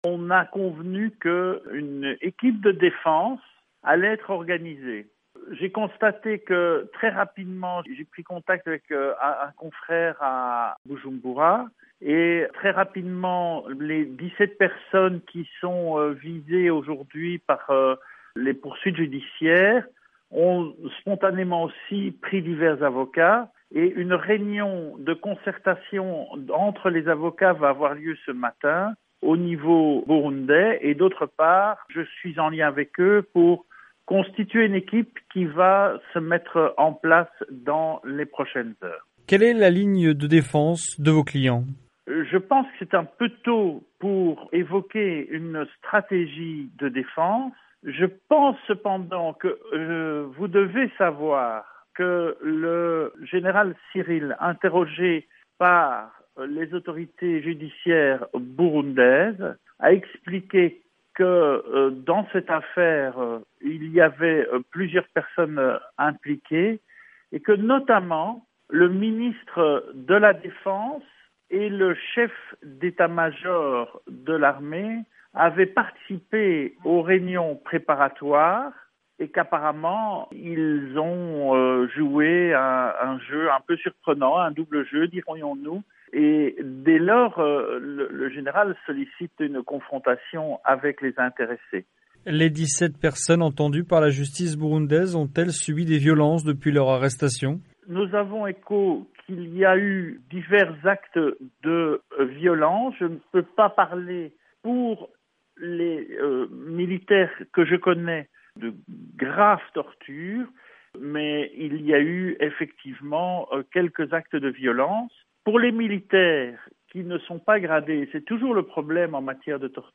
joint à Bruxelles